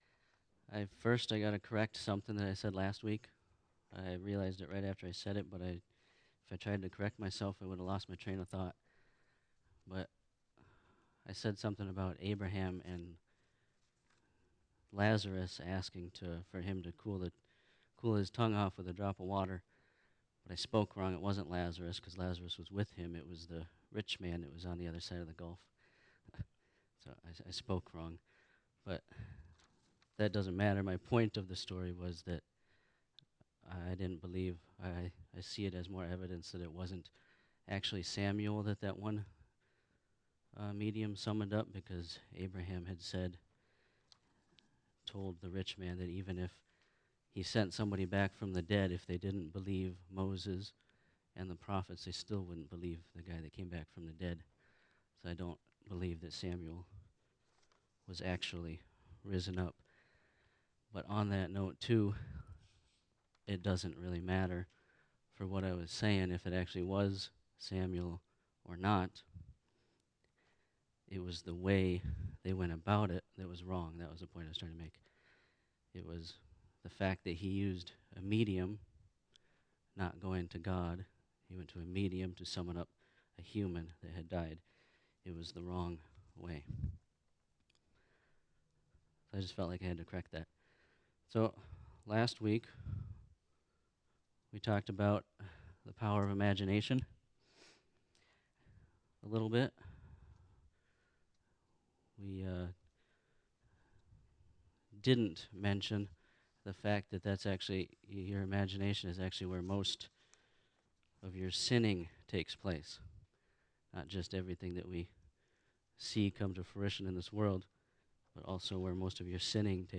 2025 Sunday Afternoon Class